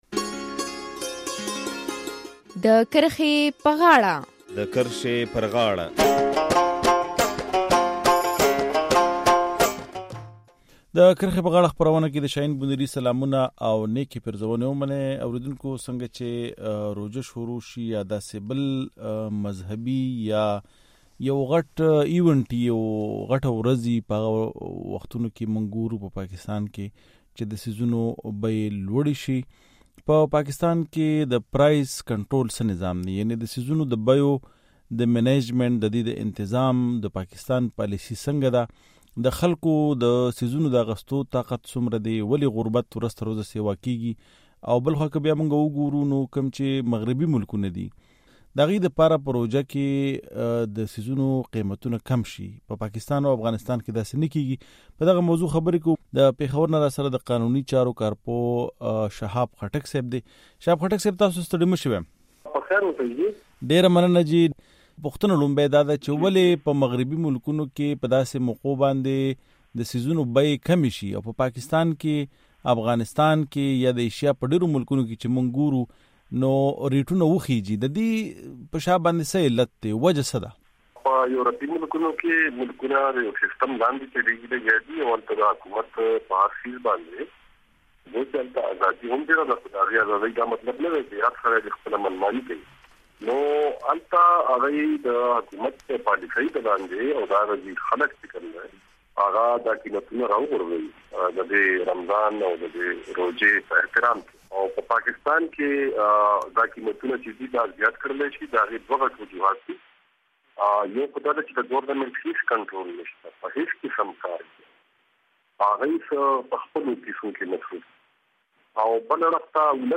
په داسې حال کې چې د مذهبي رسوماتو په وخت په مغربي هېوادونو کې د څیزونو نرخونه کم کړل شي په پاکستان کې په روژه کې څیزونه نور هم ګران شي. د دې په شا علتونه څه دي؟ د کرښې پر غاړه خپرونه کې پر دغه موضوع بحث کوو.